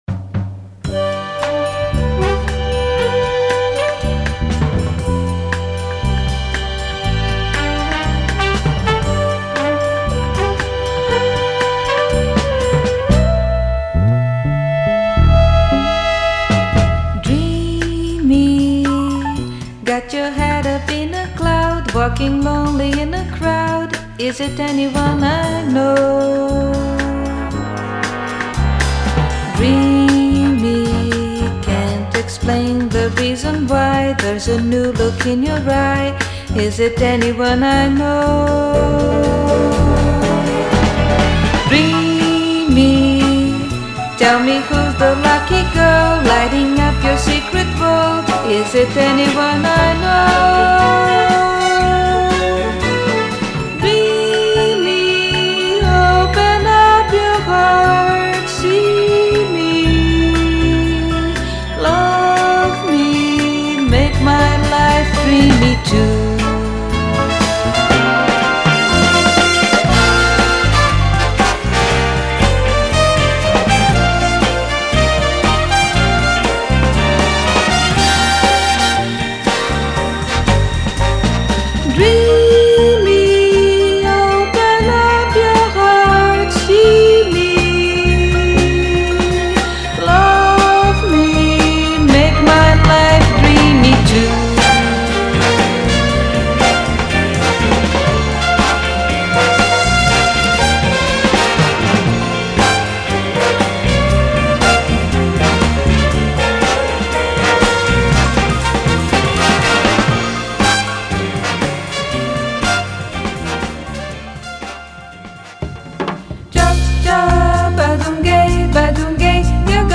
música relajada y cálida para todos los públicos.
ese ligero y agradable punto psicodélico de la época